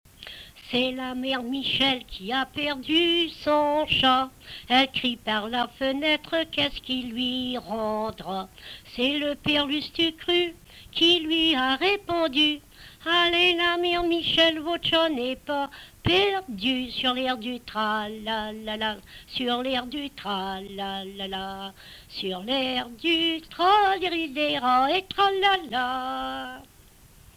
Emplacement Miquelon